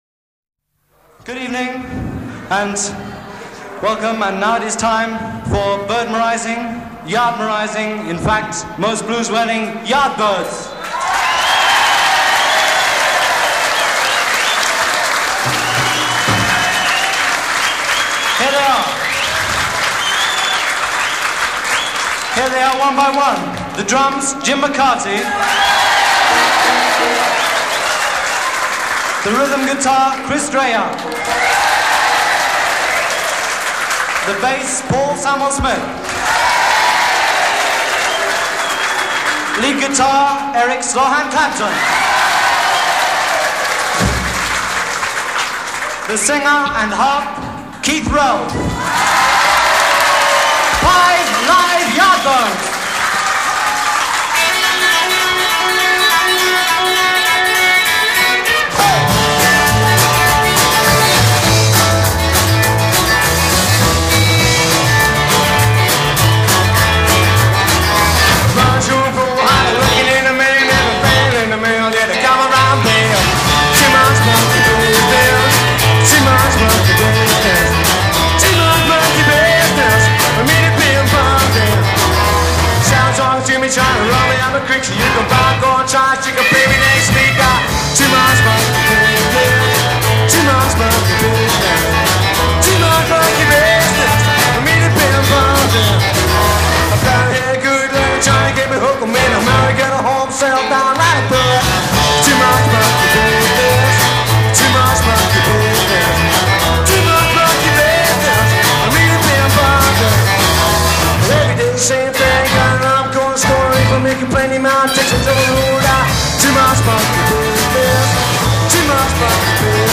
Intro 0:00 12 Guitar sets tempo; joined by ensemble
verse 1 0: 12 vocal solo with stops a
verse 6 : 12 guitar solo; build bass accompaniment
British Blues